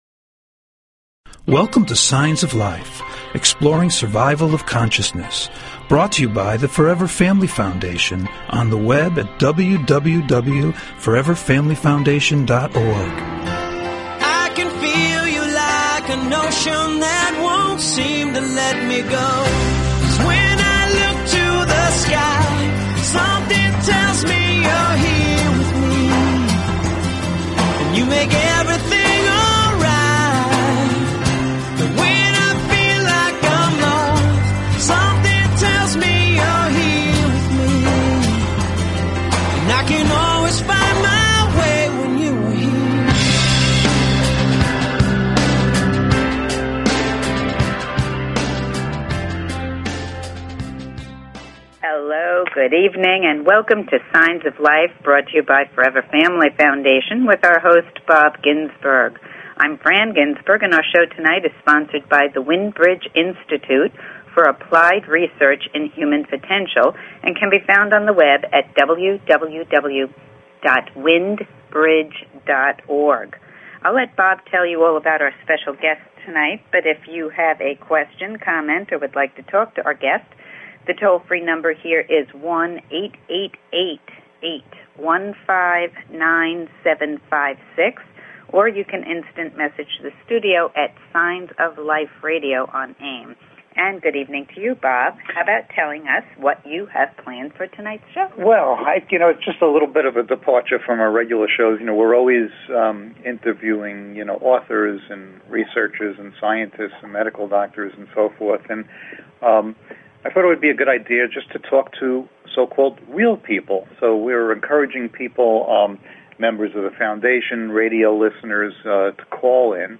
Talk Show Episode, Audio Podcast, Signs_of_Life and Courtesy of BBS Radio on , show guests , about , categorized as
SHORT DESCRIPTION - Interviews with foundation members who have lost loved ones